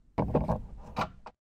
putbackcharger.ogg